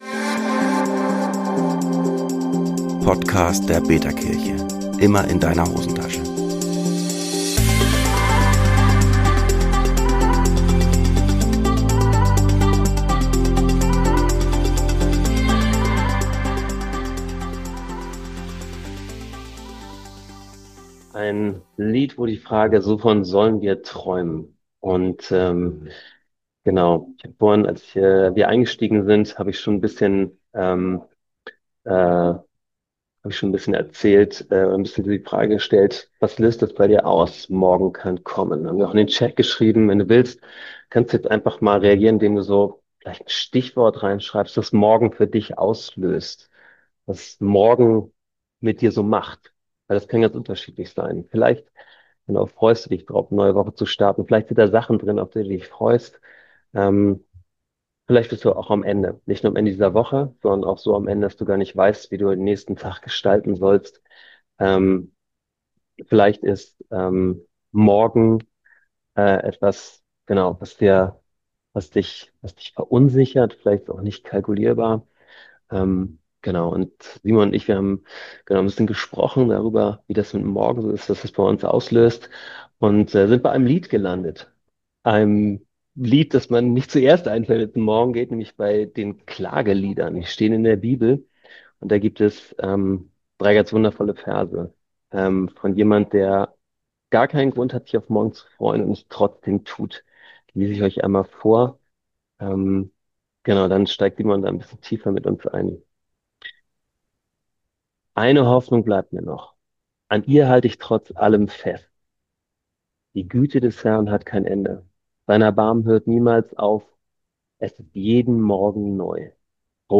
Gottesdienst | "Morgen kann kommen" Abschluss des betaKirche Festivals ~ betaKirche Podcast